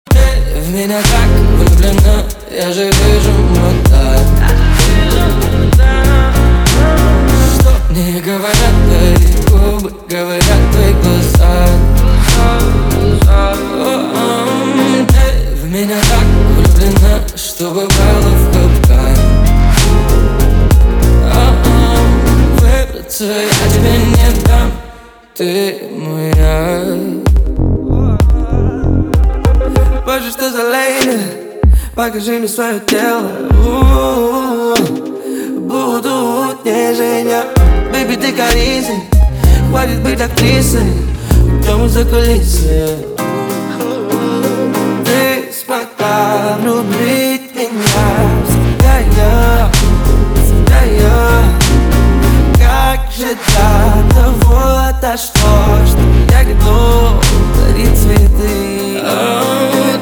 • Качество: 320, Stereo
гитара
ритмичные
мужской вокал
басы
RnB